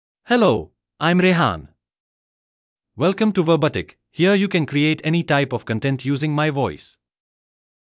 Rehaan — Male English (India) AI Voice | TTS, Voice Cloning & Video | Verbatik AI
Rehaan is a male AI voice for English (India).
Voice sample
Listen to Rehaan's male English voice.
Rehaan delivers clear pronunciation with authentic India English intonation, making your content sound professionally produced.